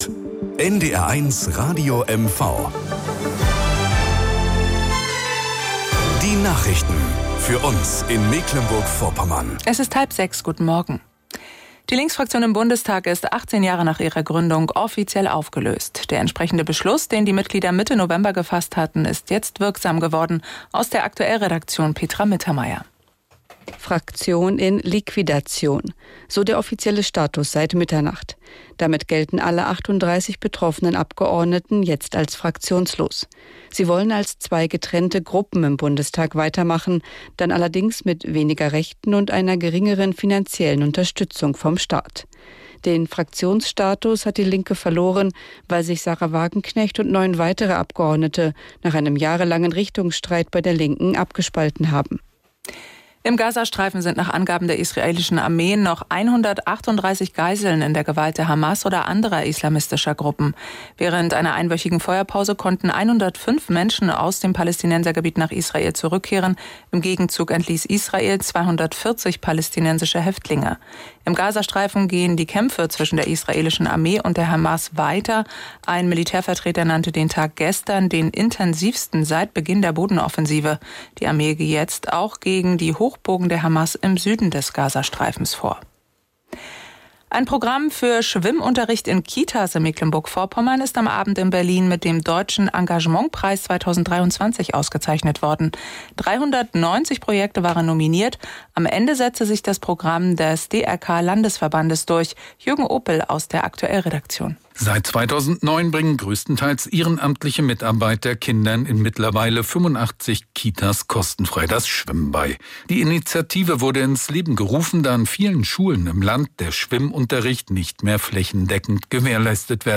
Nachrichten aus Mecklenburg-Vorpommern - 25.05.2024